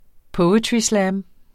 Udtale [ ˈpɔwətɹiˌslæːm ]